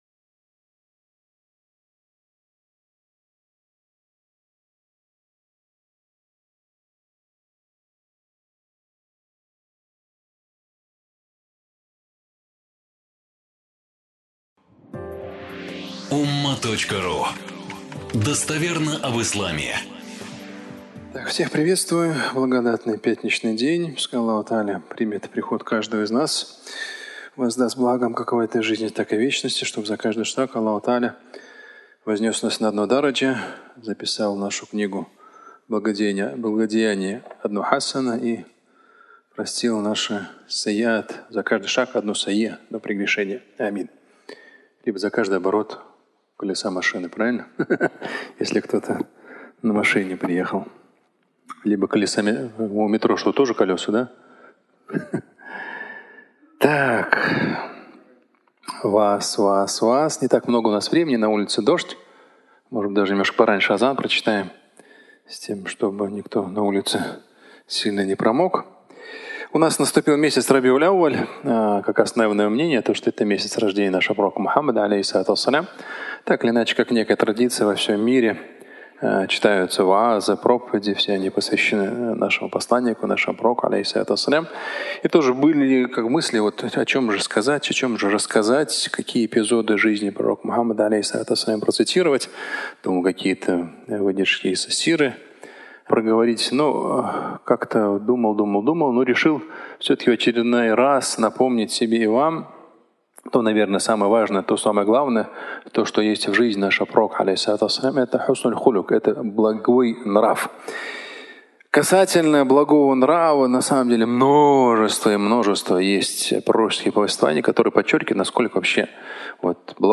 Суть религии (аудиолекция)